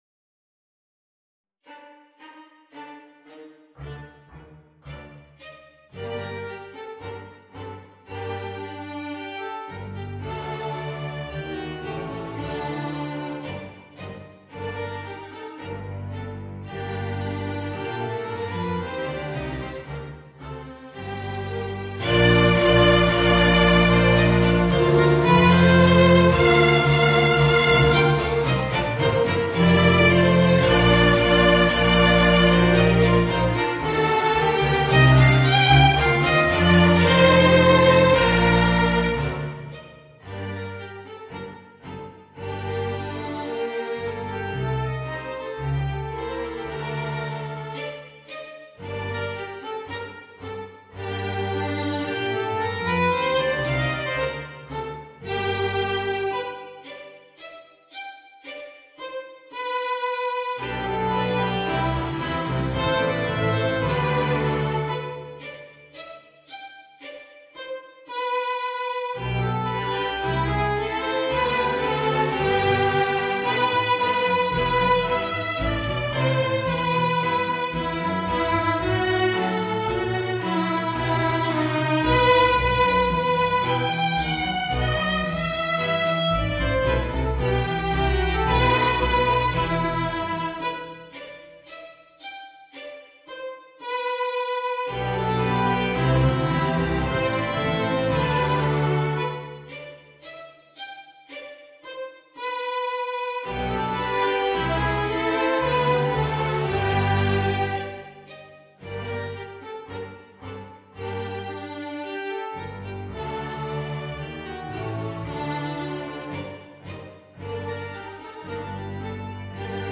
Voicing: Piccolo and String Quintet